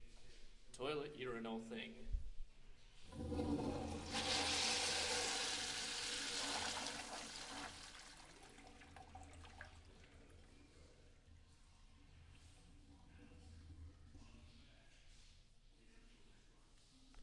小便池冲水
描述：小便池冲洗在浴室里
Tag: 缩放 法拉盛 小便器 Tollett 记录